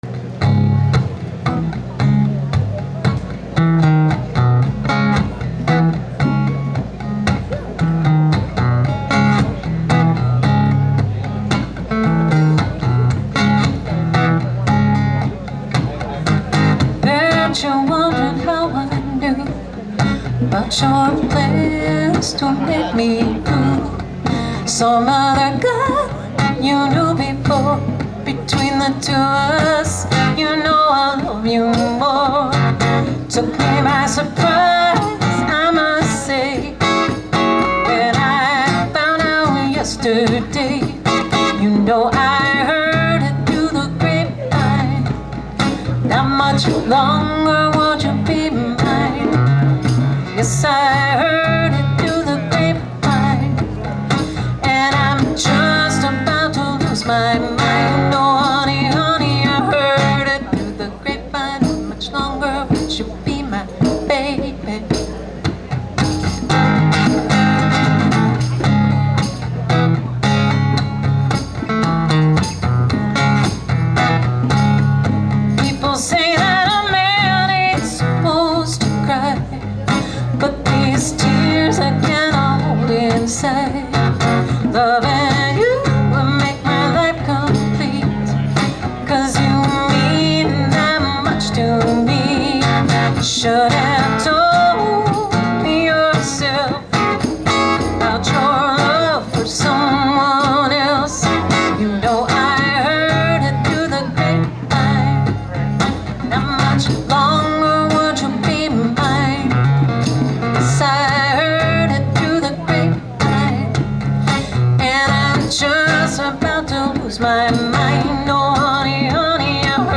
on percussion